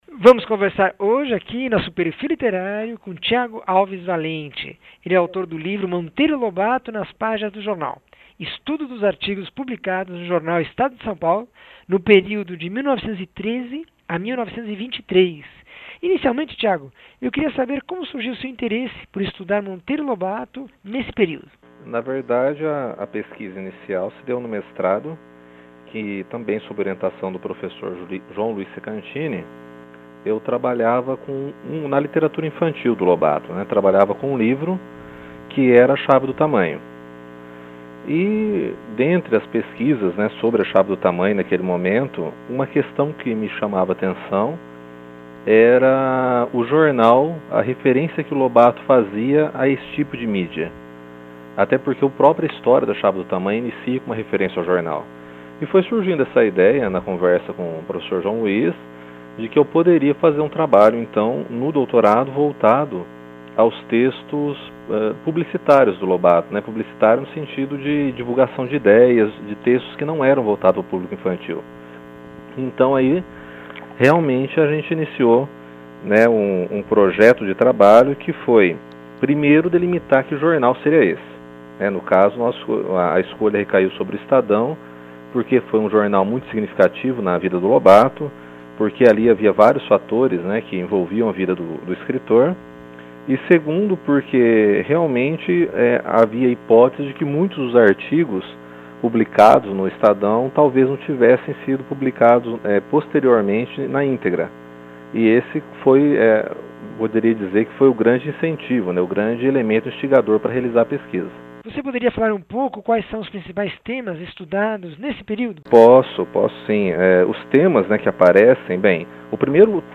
Entrevista com o escritor